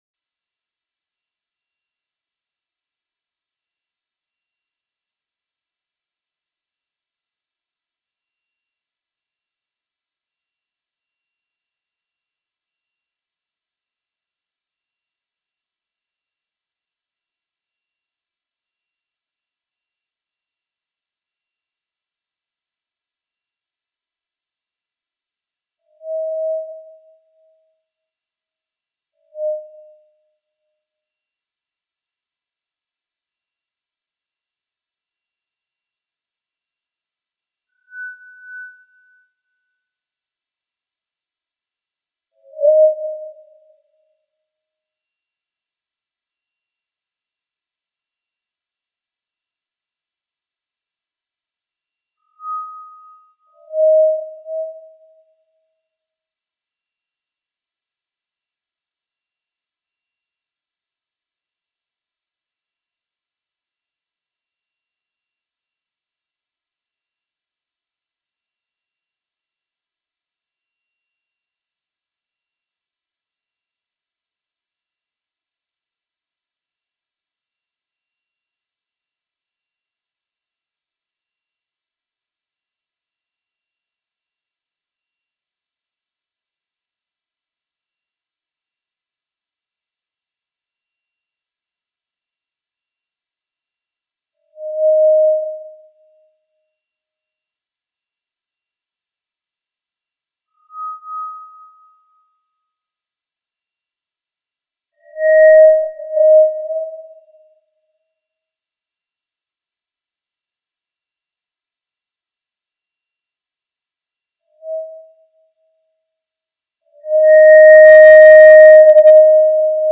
Sur le site, l’écho radio se faisait encore entendre (il a duré 40 secondes) alors que la clameur du public s’estompait!